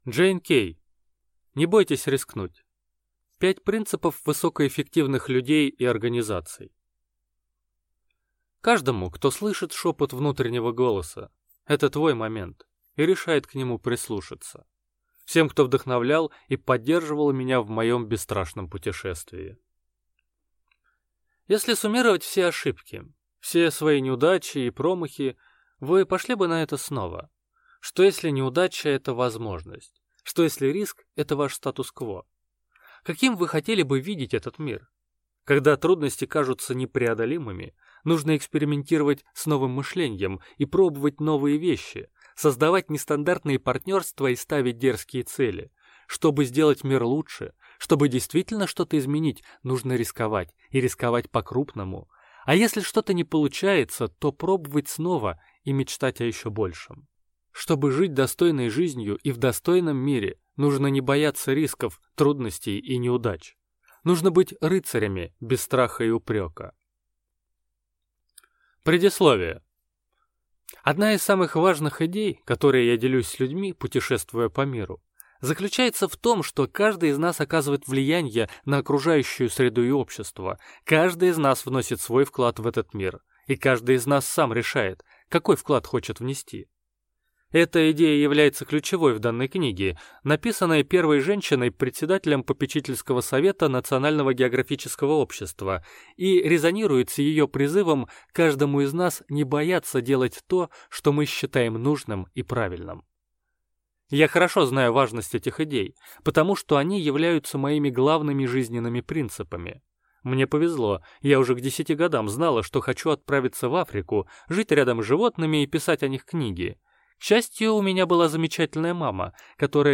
Аудиокнига Не бойтесь рискнуть! 5 принципов высокоэффективных людей и организаций | Библиотека аудиокниг